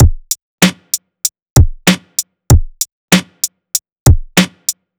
FK096BEAT1-R.wav